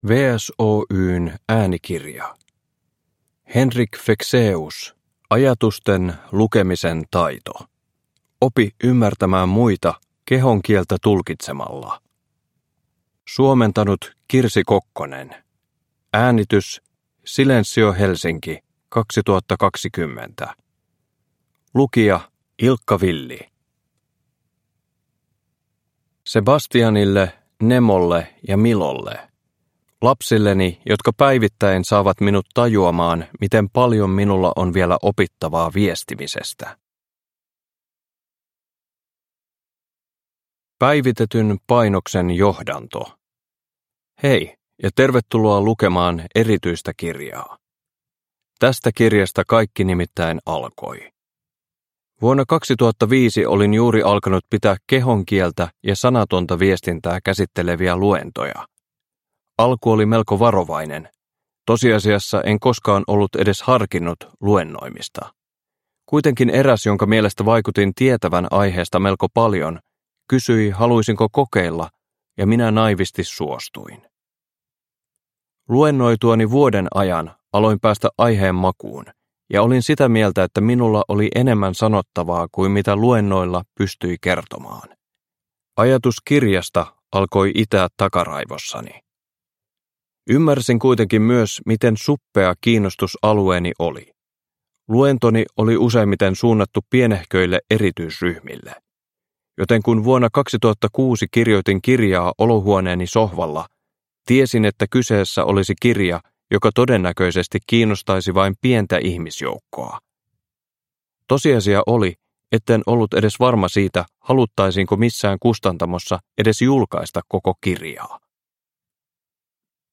Ajatusten lukemisen taito – Ljudbok – Laddas ner